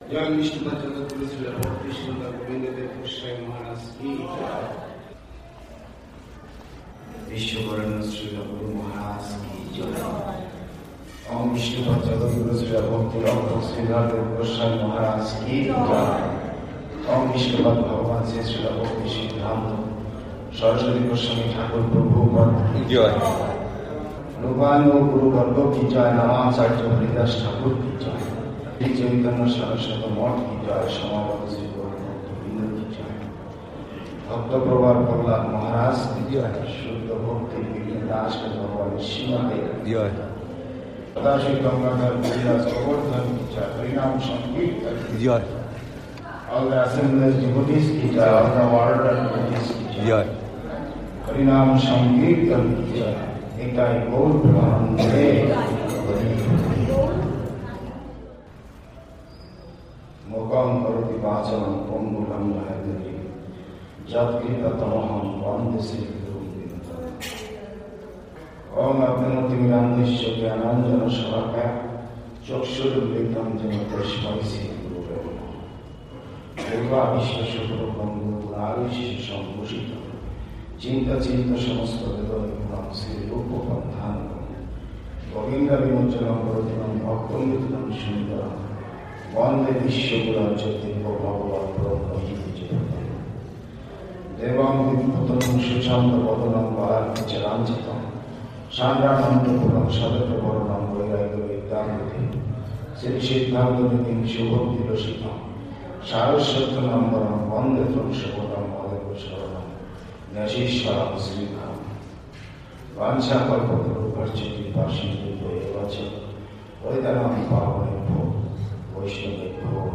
Sri Nrisingha Palli, evening class 2 January 2021, part 4, translated from Bengali